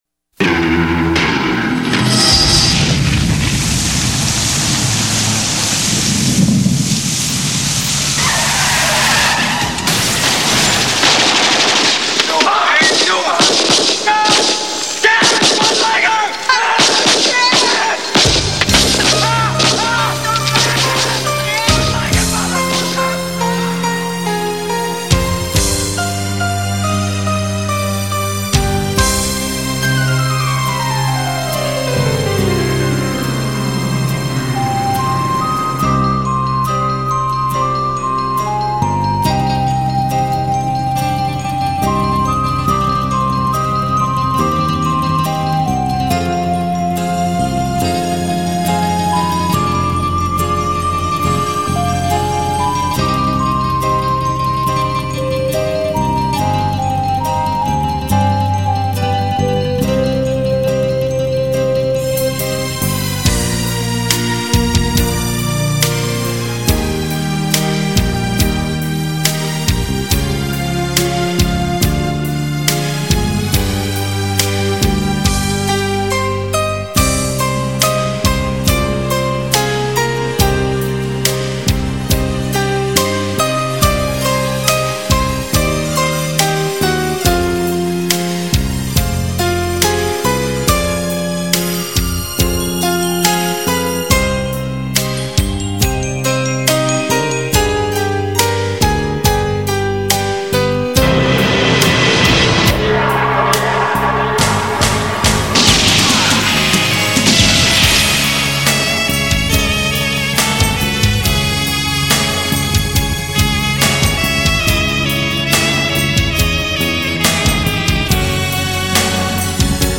早期的電子混音雷射效果
音响效果很强！